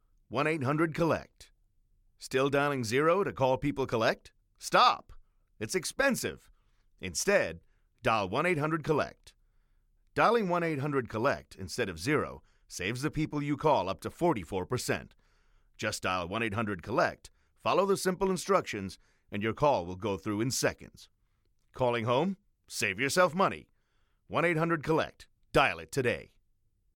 American, US, UK, British, English, Hindi, Indian accent, deep, medium, elder, adult, narration, audiobook, upbeat, drama, explainer, clear
Sprechprobe: Werbung (Muttersprache):
I am a native English speaker; my primary delivery is with a North American accent, and I quite often deliver projects using British English as well. Additionally, I am able to record from home using a professional microphone (RODE NT) and Audacity.